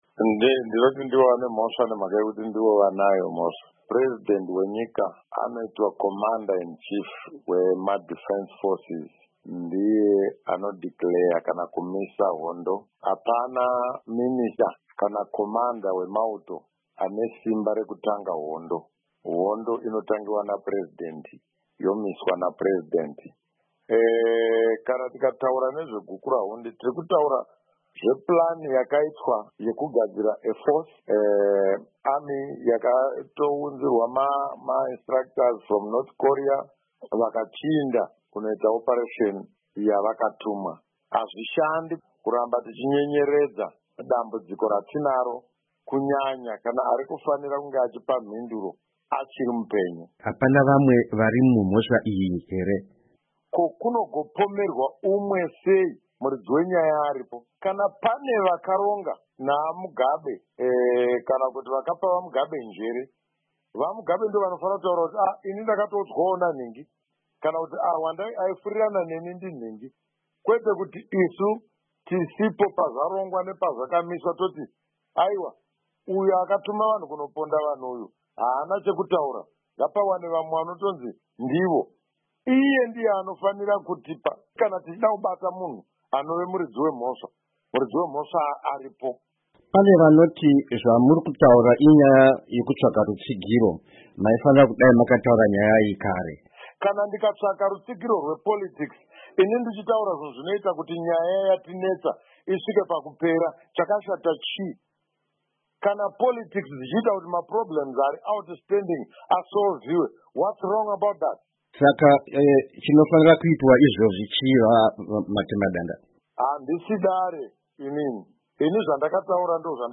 Hurukuro naVaVictor Matemadanda